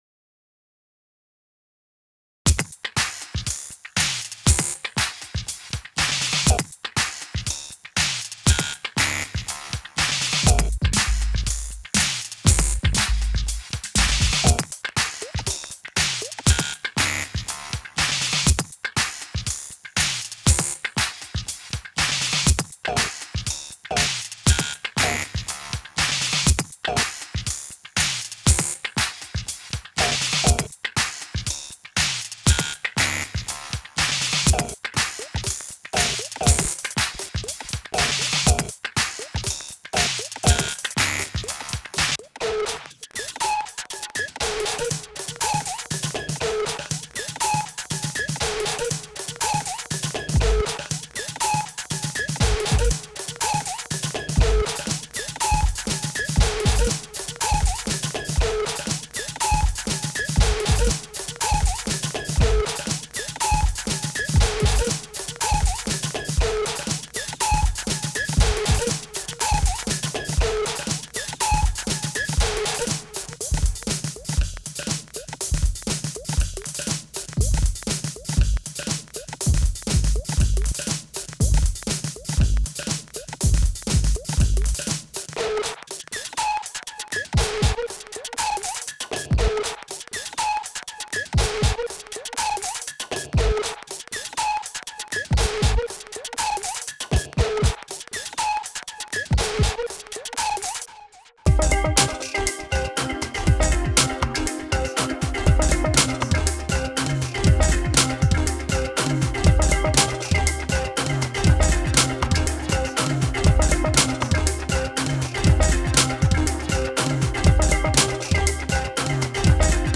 Dabei handelt es sich um Rhythmology, eine moderne Rhythmus Software, überwiegend für moderne Musik Produktionen.